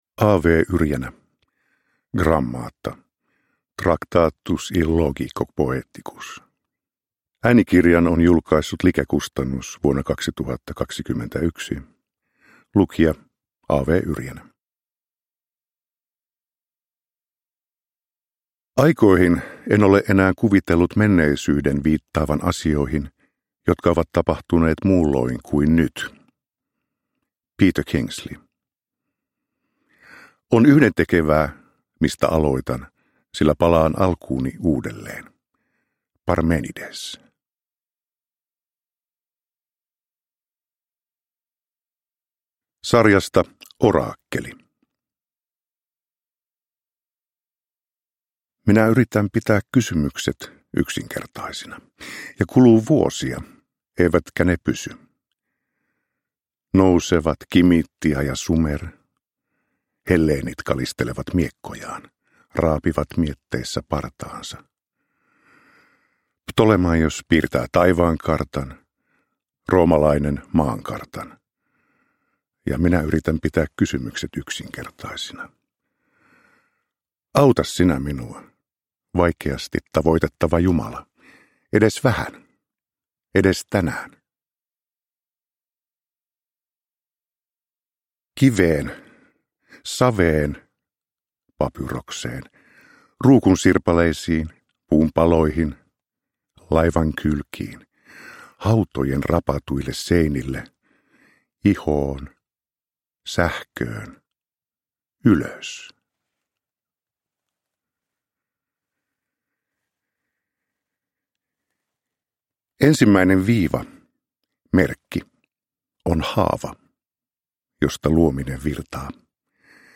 Grammata – Ljudbok – Laddas ner
Uppläsare: A. W. Yrjänä